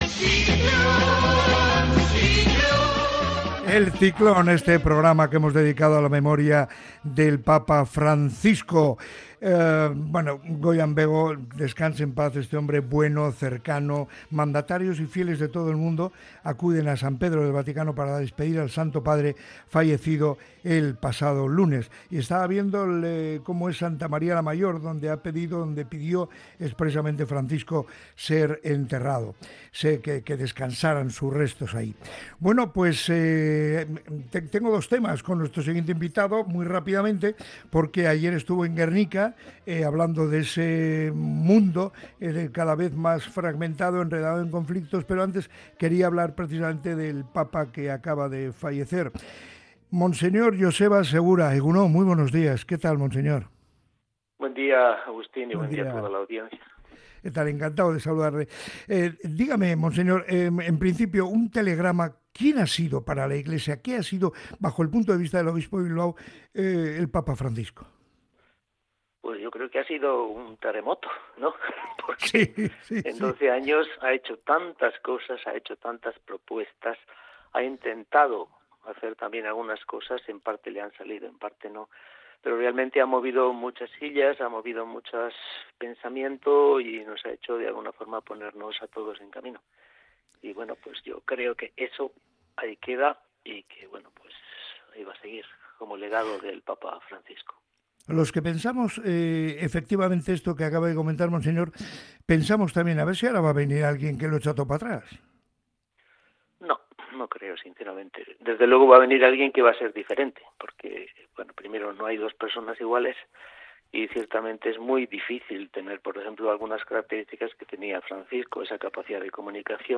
El obispo de Bilbao, Joseba Segura, ha intervenido en el programa Moliendo Café de Radio Popular – Herri Irratia para recordar la figura del Papa Francisco, fallecido recientemente.